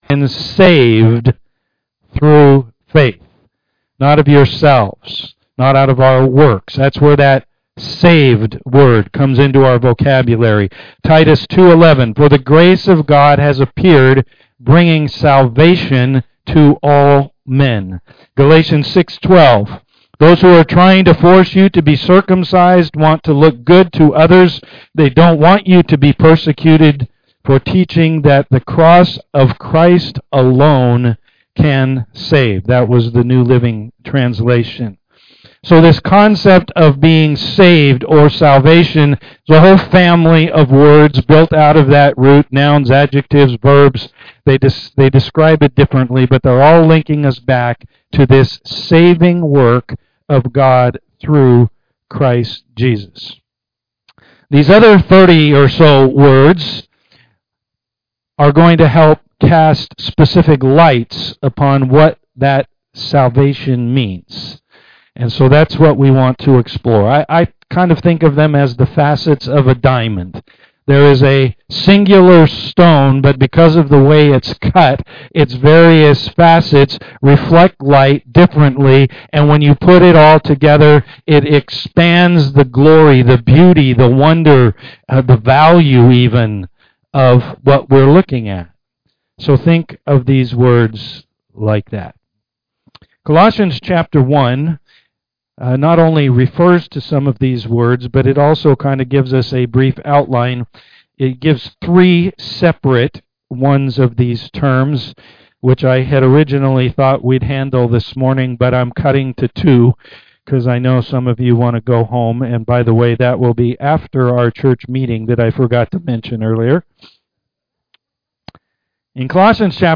[note: the audio file did not begin immediately.
Bible Greats Service Type: am worship The Bible calls the work of God to bring us spiritual life salvation.